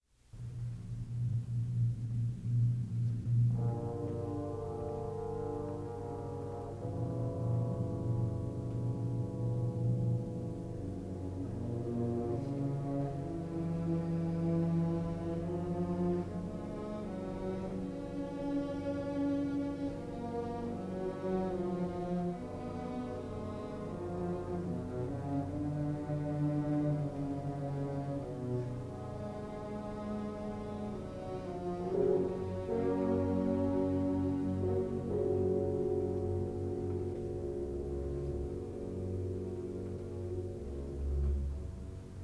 Stereo recording made in February 1960